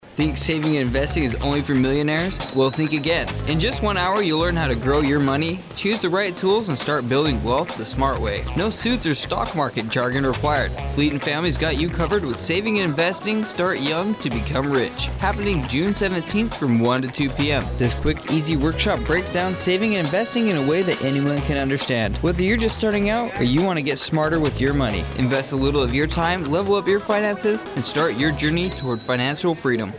Radio spot promotes Saving and Investing: Start young to become rich, a program offered through the Fleet and Family Support Center Sigonella.